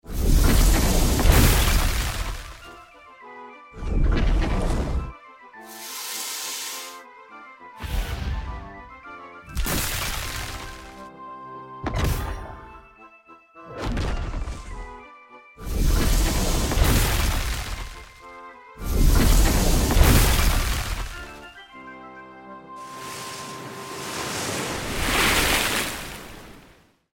❄빙결 마법 스킬 사운드는 어떻게 sound effects free download
냉기, 파열, 마법의 느낌까지—소리를 조합해 얼음 마법을 완성합니다. 🎧 사운드 요소 분해 → 레이어링 → 최종 믹스 단순한 '펑!'이 아닌, 캐릭터와 세계관에 어울리는 소리로!